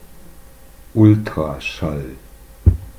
Ultraschall (German: Ultrasound, German: [ˈʊltʁaʃal]
Ultraschall_-_German_pronounciation.ogg.mp3